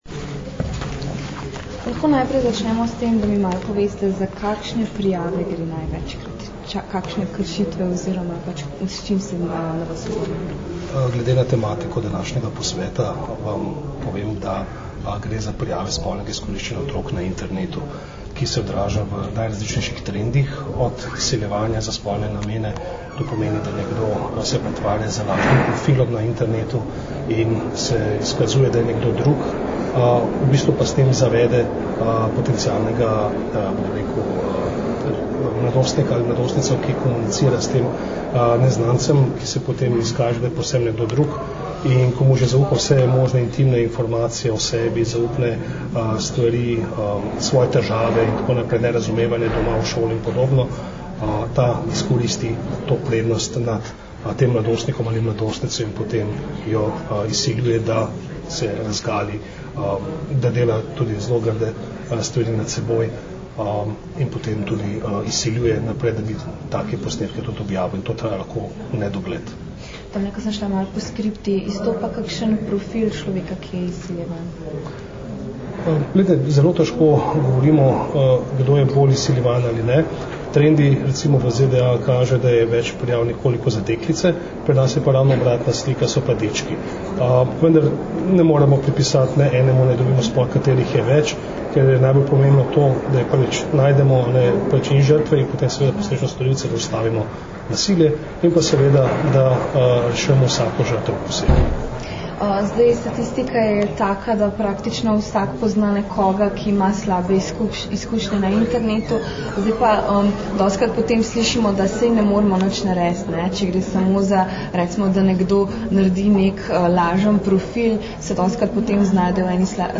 Na že petem posvetu E-zlorabe otrok, ki se je danes odvijal na Fakulteti za družbene vede v Ljubljani, so strokovnjaki opozorili na zlorabe otrok prek mobilnih naprav.
Zvočni posnetek izjave